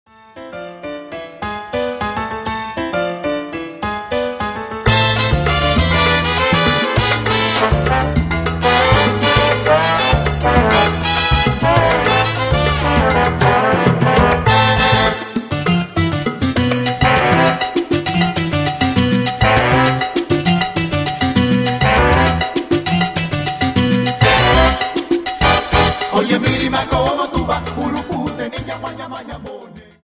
Dance: Salsa 50